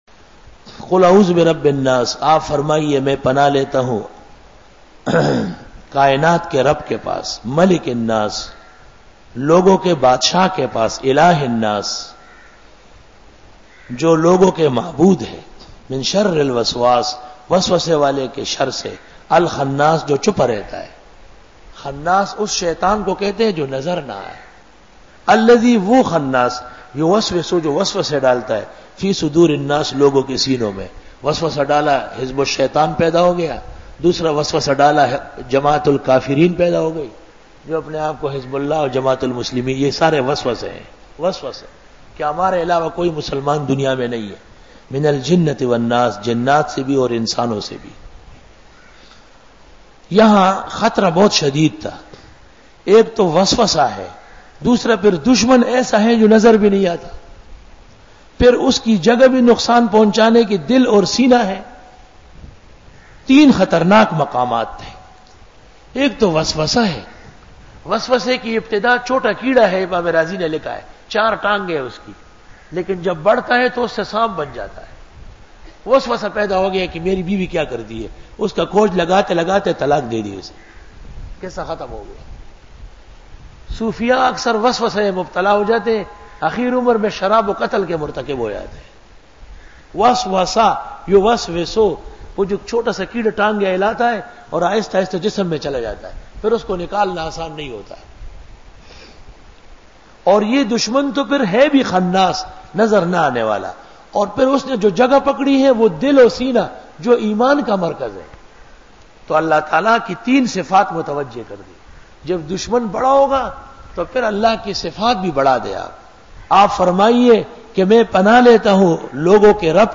Dora-e-Tafseer 2001